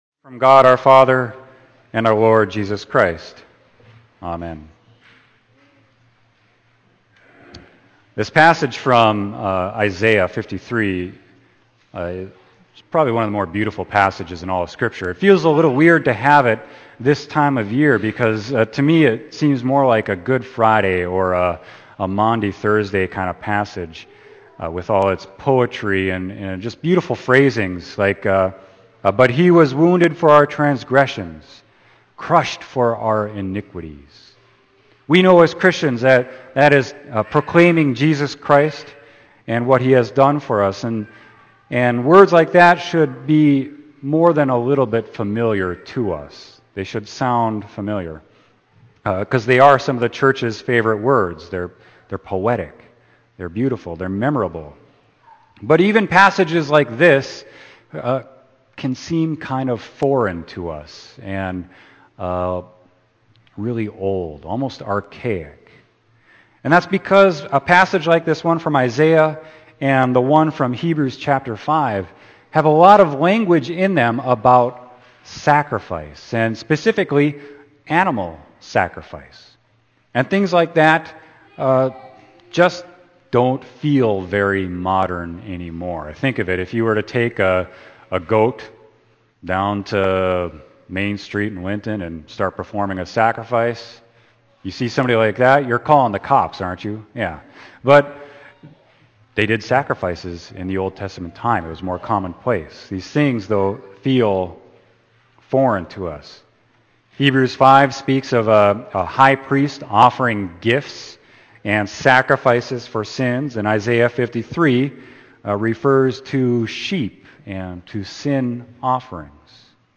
Sermon: Isaiah 53.4-12